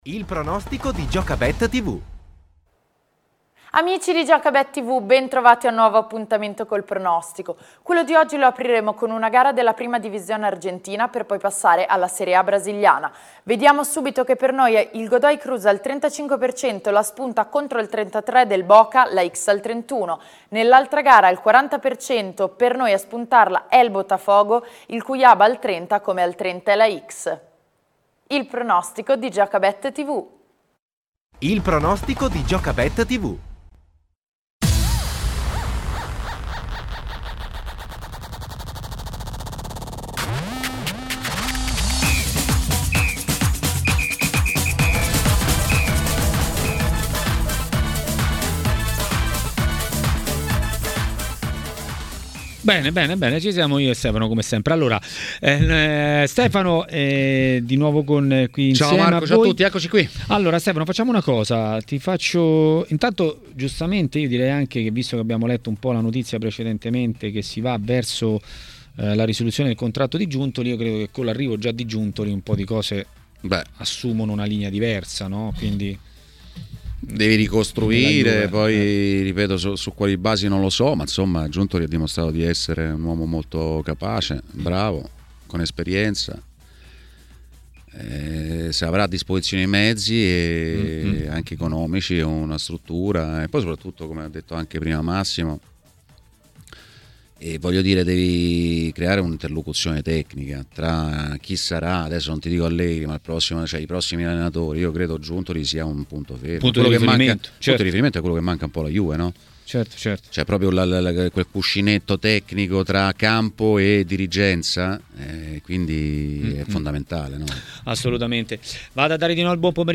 Ospite di TMW Radio, durante Maracanà, è stato l'ex calciatore e allenatore Roberto Sosa.